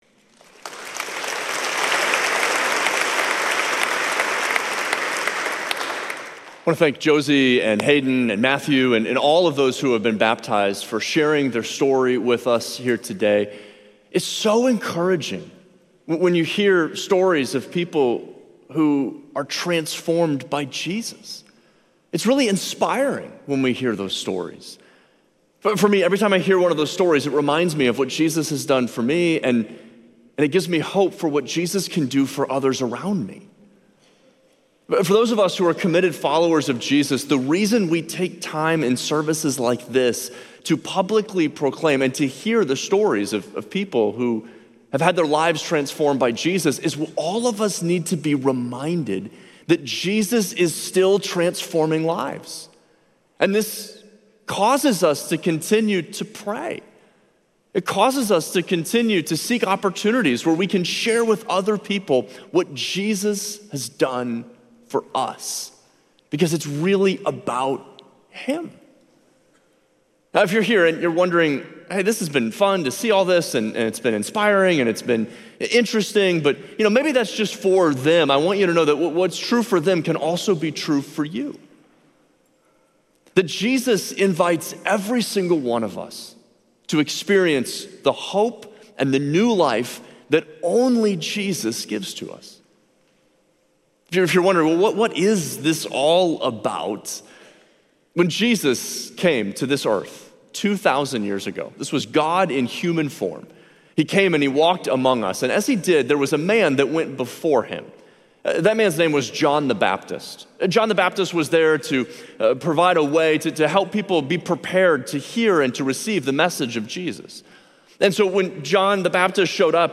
Watch the full service with baptisms and stories > Share this Sermon Facebook Twitter Previous Receive It!